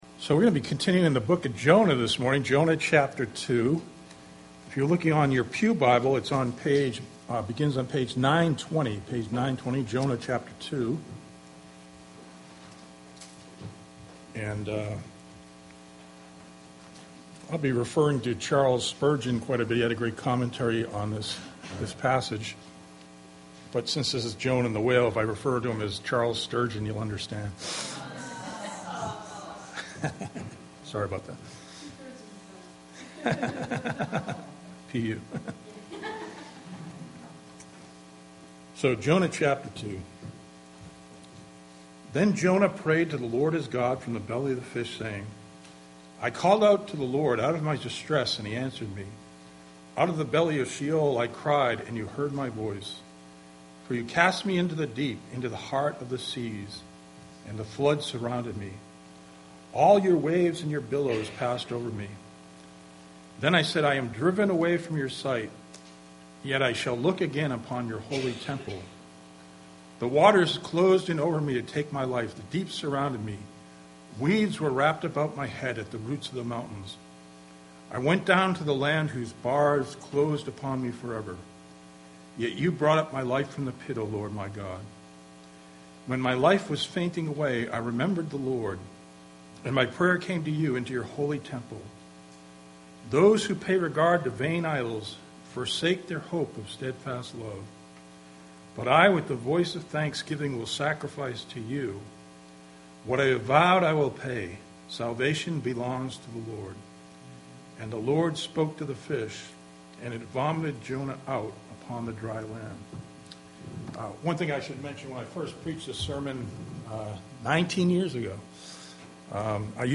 Bible Text: Jonah 2 | Preacher